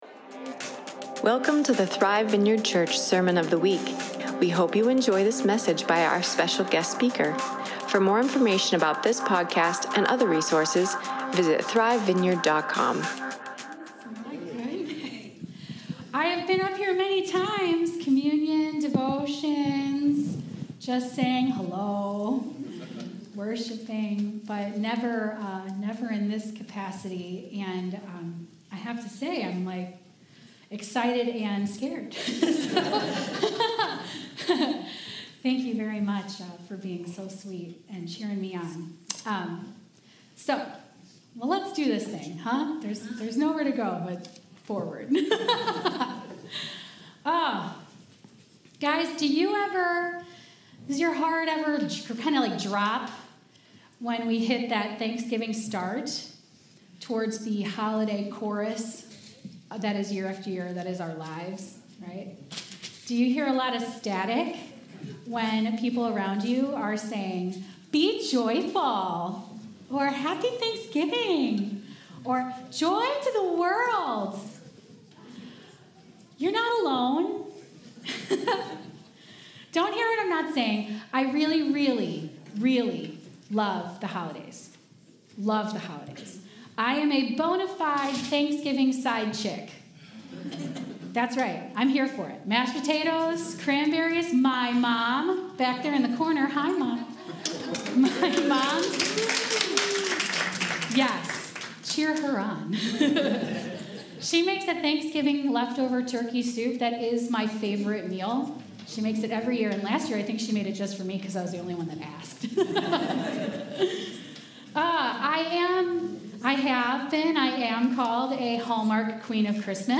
2024 Sunday Service Does your heart ever drop leading into the Thanksgiving start to the “Holiday" chorus of your life?